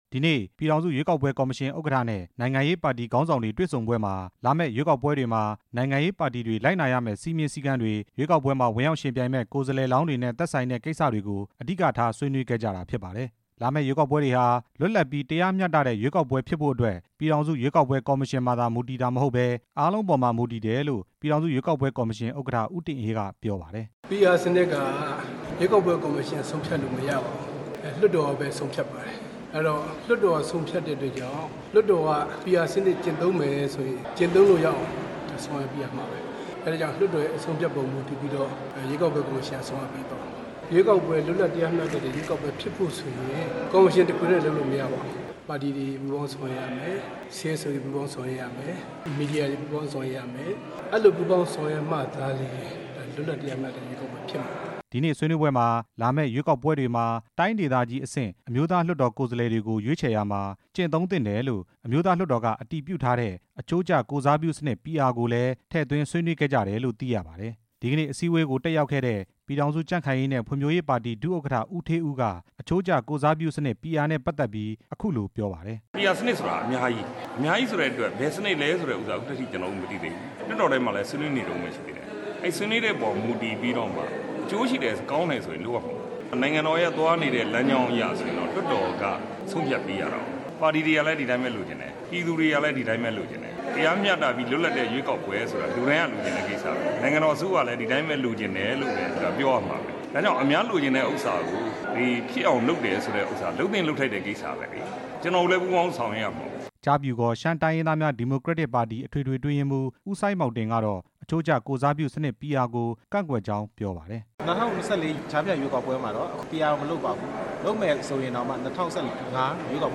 ဒီကနေ့ နေပြည်တော် ပြည်ထောင်စုရွေးကောက် ပွဲ ကော်မရှင်ရုံးမှာ ကော်မရှင် ဥက္ကဌနဲ့ နိုင်ငံရေး ပါတီတွေ တွေ့ဆုံပွဲအပြီး ကျင်းပတဲ့ သတင်းစာ ရှင်းလင်းပွဲမှာ ကော်မရှင် ဥက္ကဌ ဦးတင်အေးက ပြောခဲ့တာဖြစ်ပါတယ်။